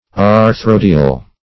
Search Result for " arthrodial" : The Collaborative International Dictionary of English v.0.48: Arthrodial \Ar*thro"di*al\, Arthrodic \Ar*throd"ic\, a. Of or pertaining to arthrodia.